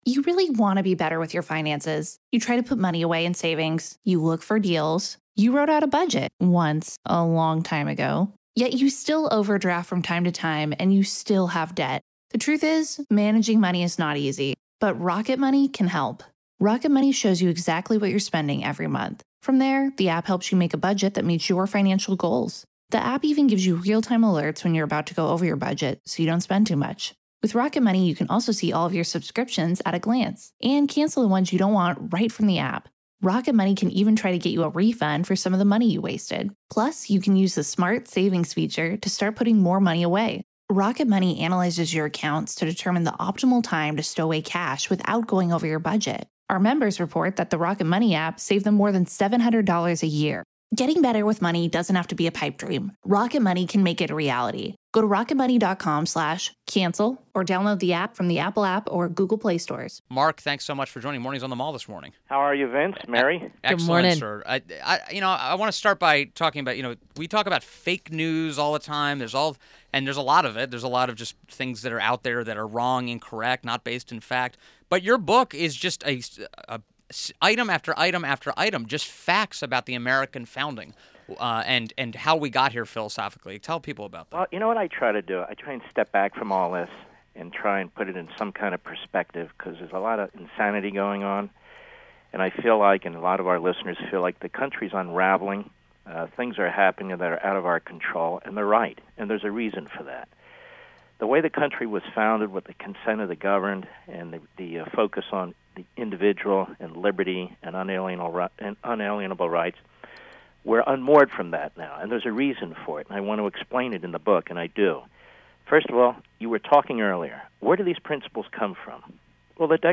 WMAL Interview - MARK LEVIN 06.29.17